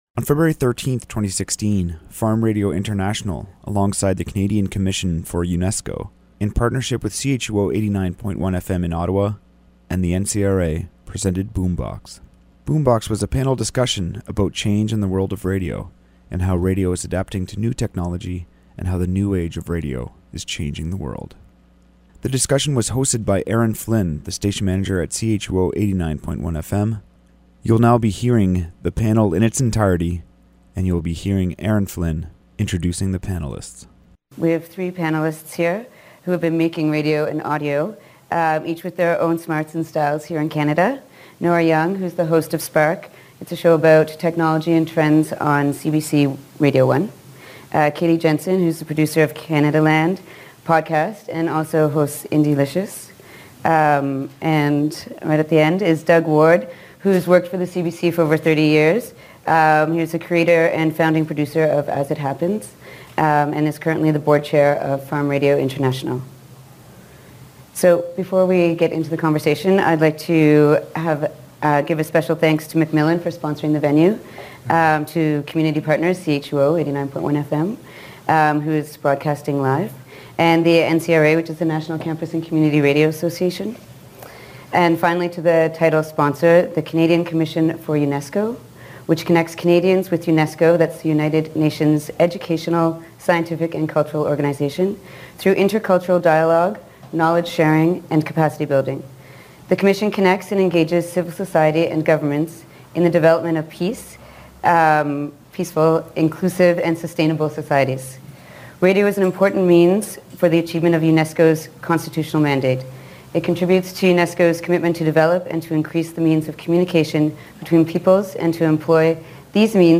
Celebrating Live from Ottawa World Radio Day Feb 13 2016